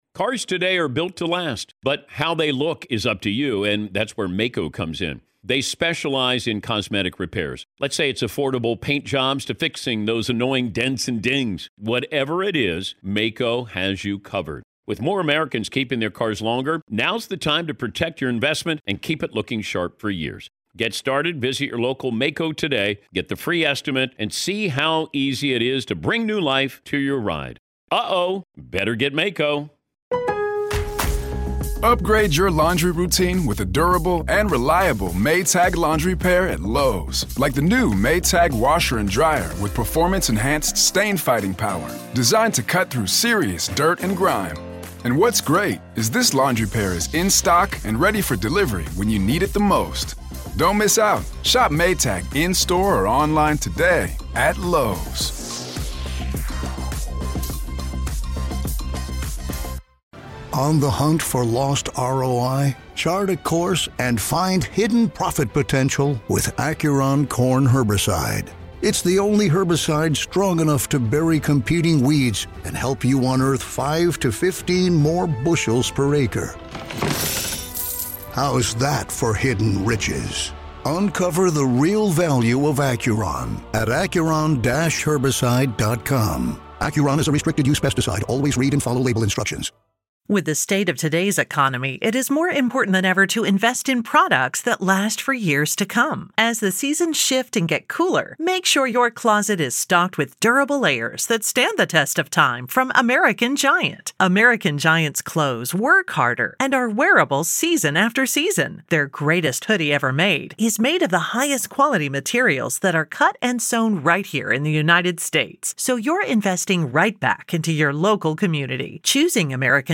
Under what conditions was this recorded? COURT AUDIO-Missing Mom Murder Trial